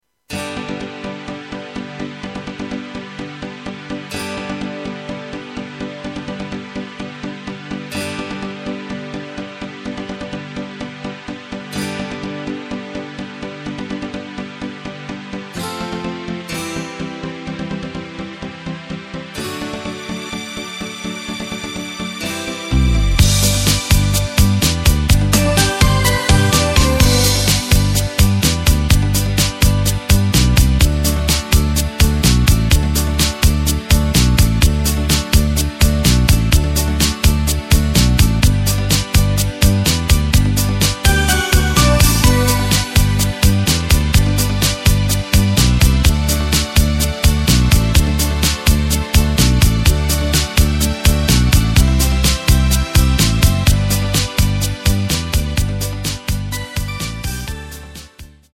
Takt: 4/4 Tempo: 126.00 Tonart: G
Schlager aus dem Jahr 1986!
mp3 Playback Demo mit Lyrics